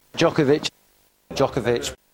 In England, the beginning is pronounced like jock, while in the US it’s pronounced like joke. Here are two BBC commentators. First, Englishman Tim Henman: